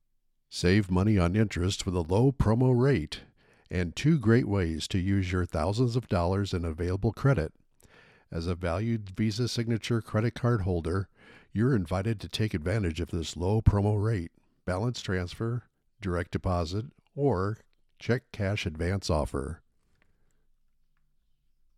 Demos
Dry read
English - Midwestern U.S. English
Middle Aged
Senior